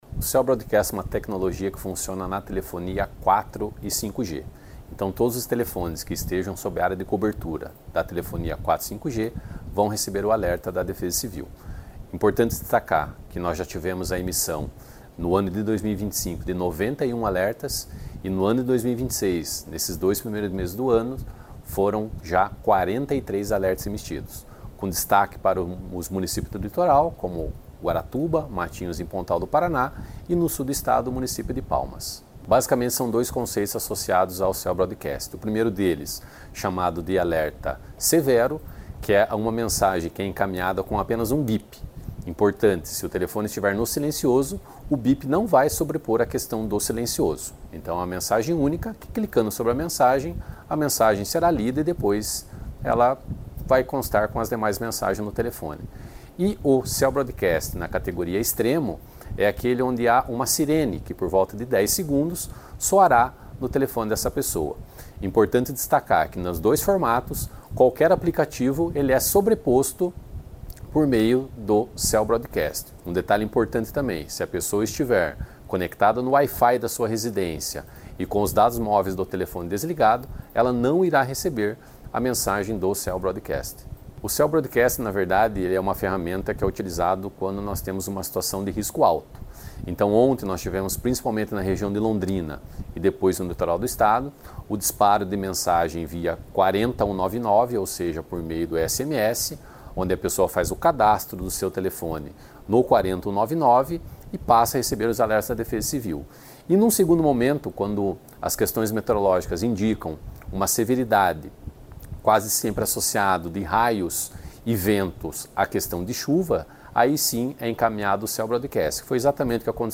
Sonora do coordenador-executivo da Defesa Civil, coronel Ivan Ricardo Fernandes, sobre o Cell Broadcast